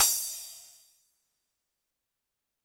Drums_K4(20).wav